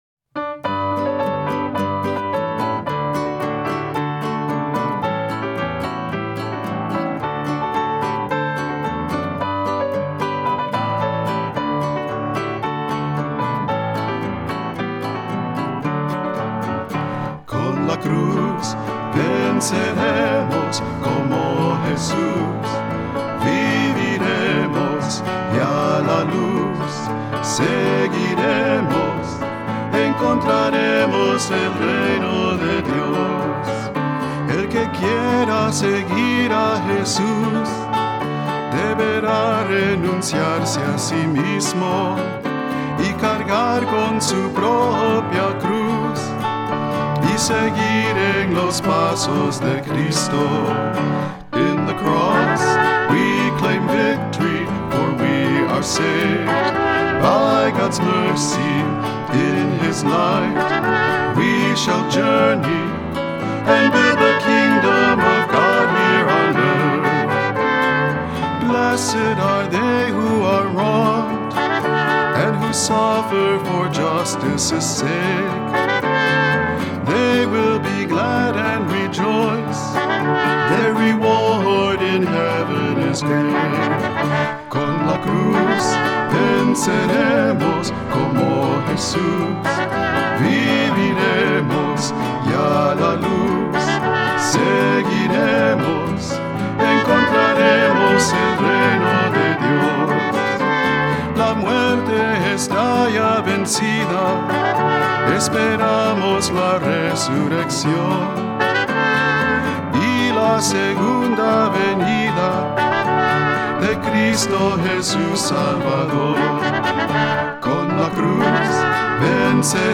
Voicing: 2-part Choir, assembly, cantor